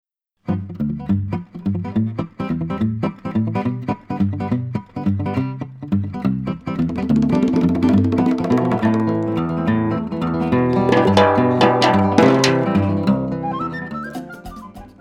Choro brasileiro